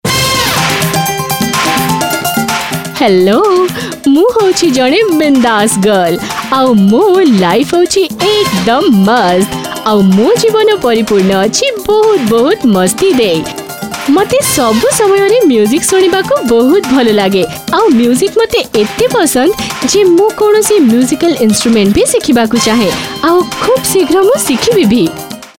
Oriya Voice Over Sample
Oriya Voice Over Female Artist -3